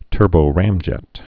(tûrbō-rămjĕt)